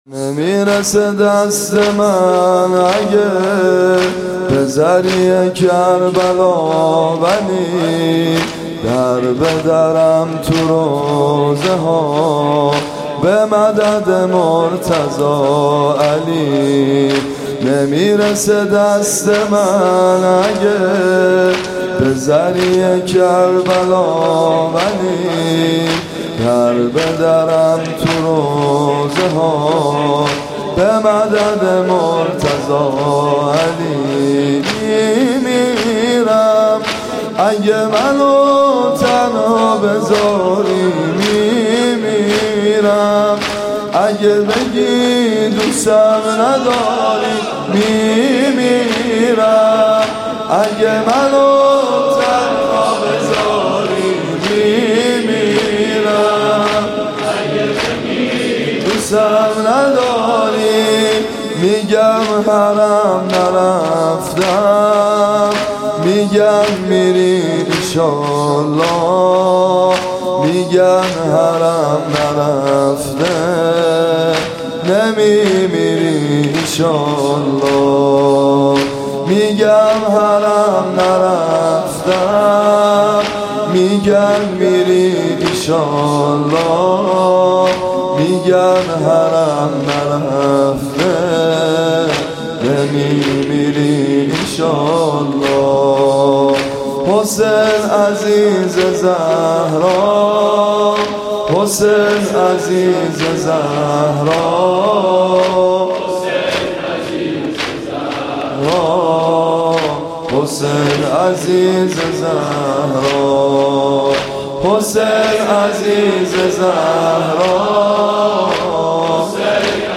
صوت مراسم شب هفتم محرم ۱۴۳۷ هیئت ابن الرضا(ع) ذیلاً می‌آید: